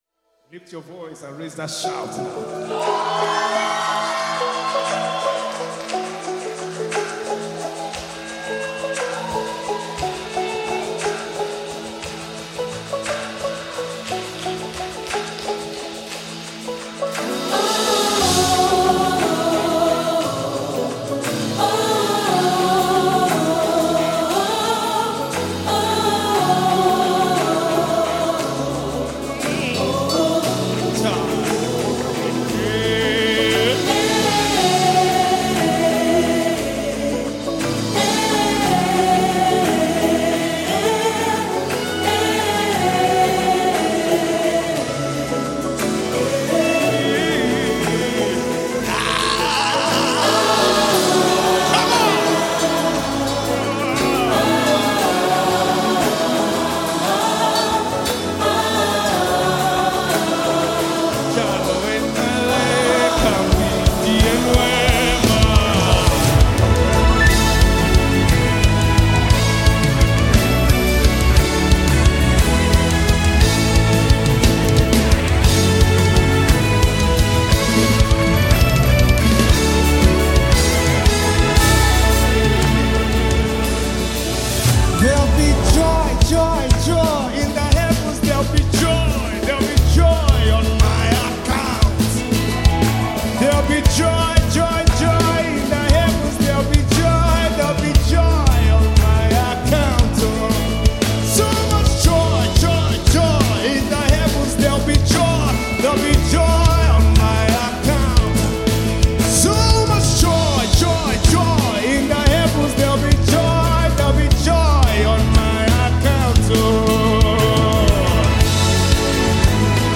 Nigerian gospel music is making waves